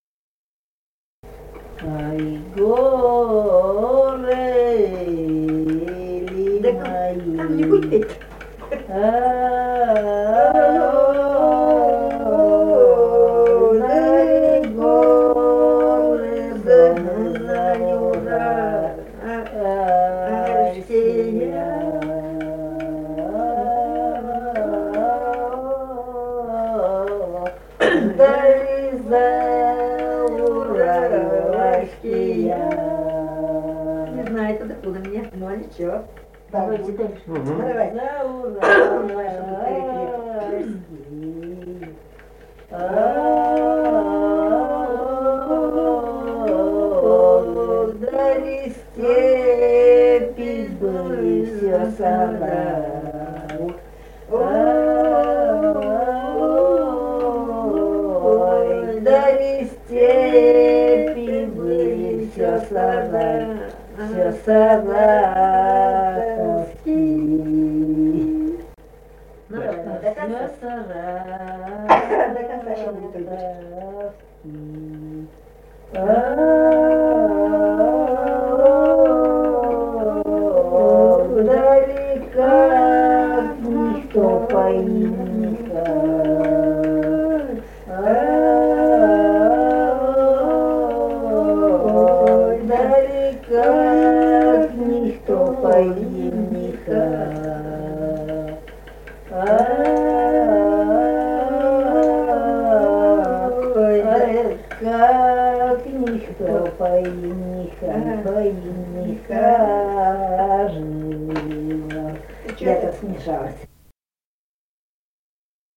Республика Казахстан, Восточно-Казахстанская обл., Катон-Карагайский р-н, с. Белое, июль 1978.
Прим.: пели в компании.